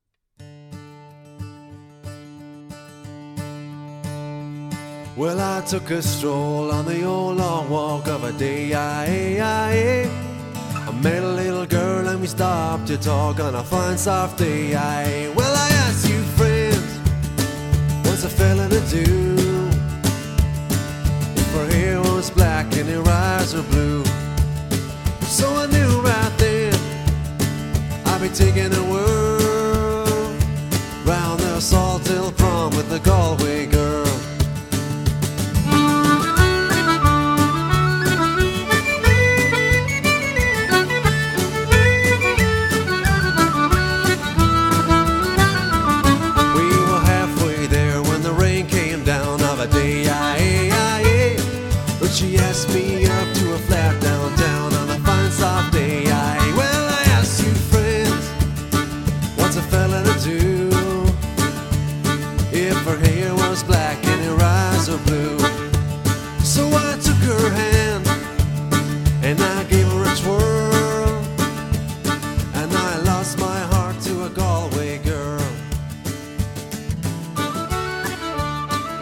• Plays a variety of traditional Irish instruments